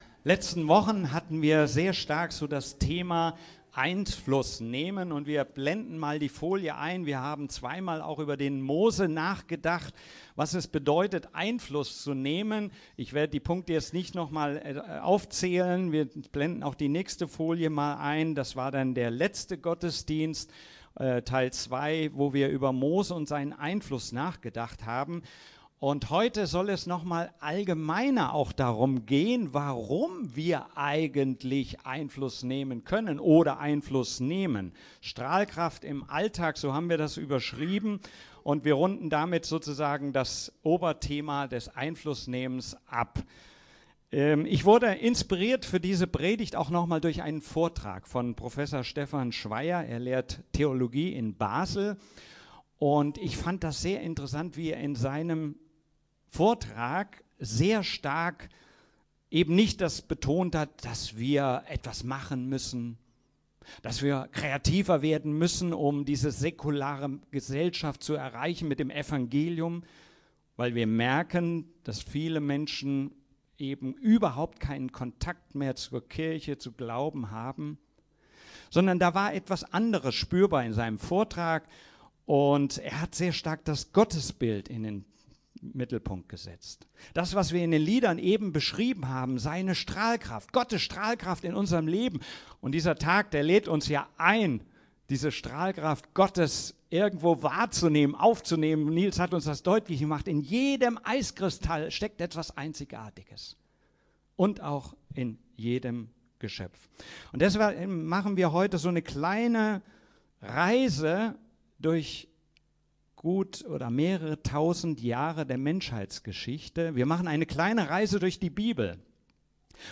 Predigten FeG Schwerin Podcast